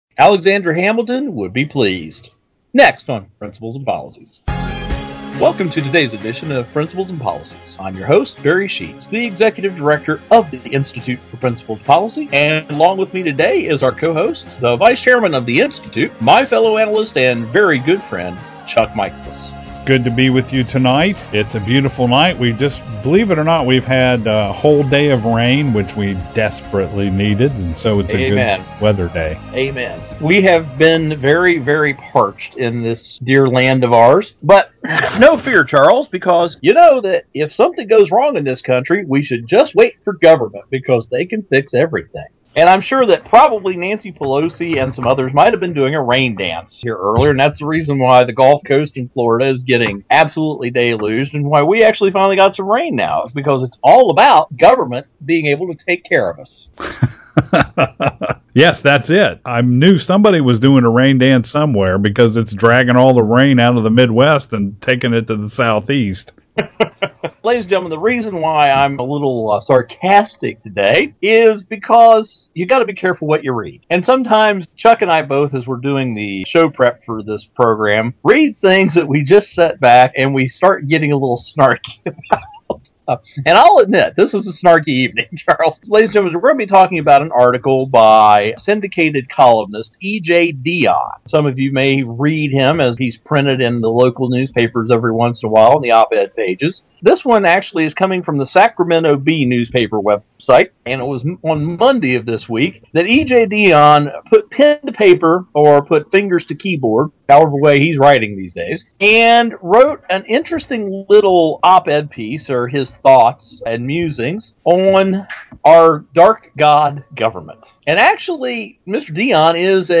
Our Principles and Policies radio show for Tuesday June 12, 2012.